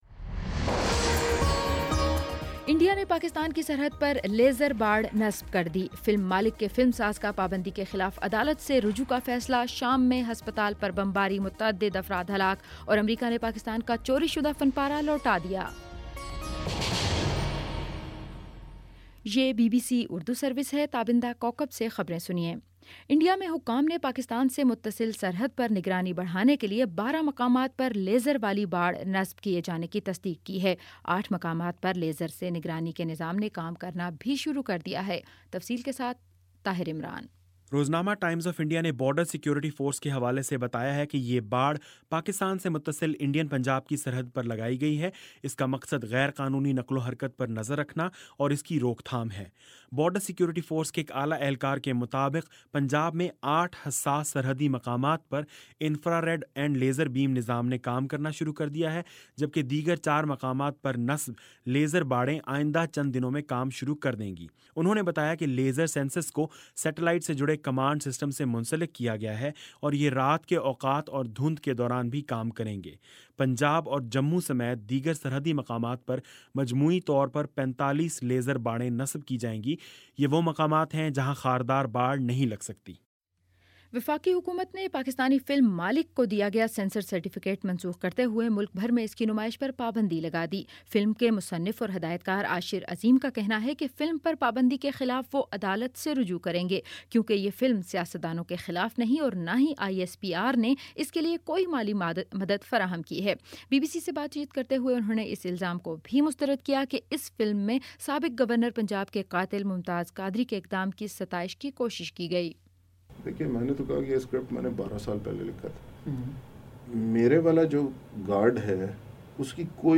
اپریل 28 : شام پانچ بجے کا نیوز بُلیٹن